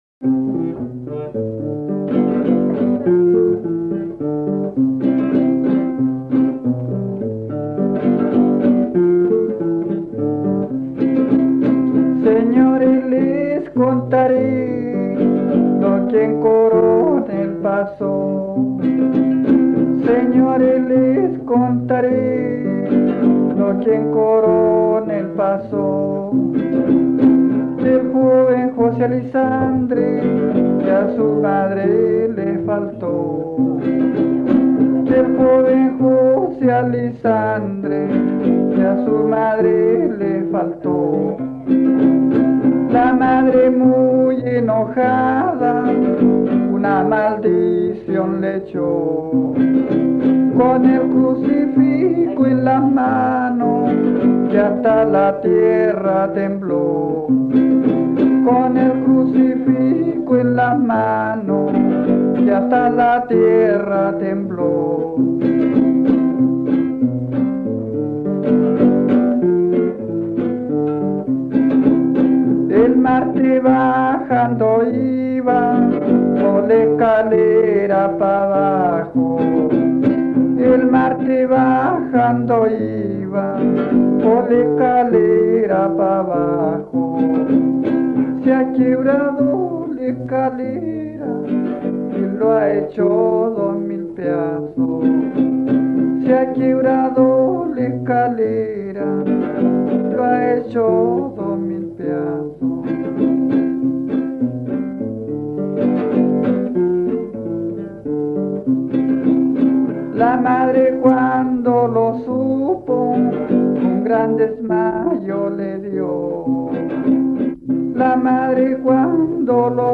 Música tradicional
Folklore
Romance
Tonada